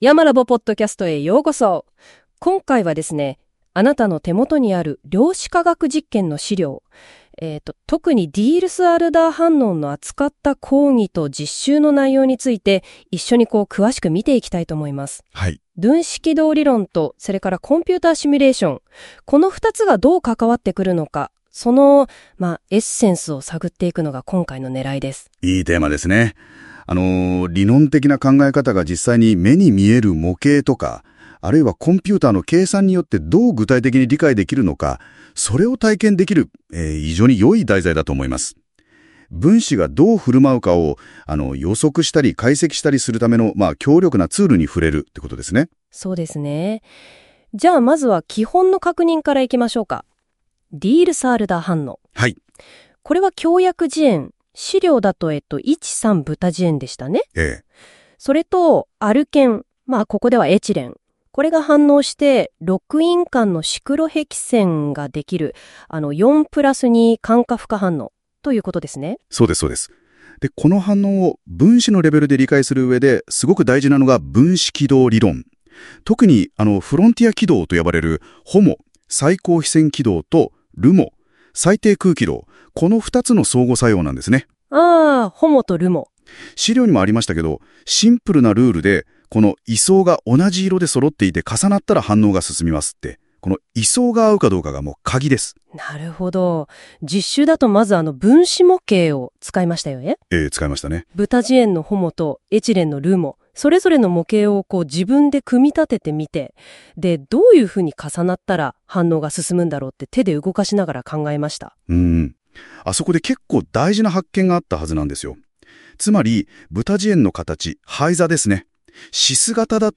量子化学実験の「音声録音 」と「スライド」をもとに、主要なトピックについて「２人のＡＩホストがおしゃべりする」というポッドキャスト風の音声概要を作りました。
Audio Channels: 1 (mono)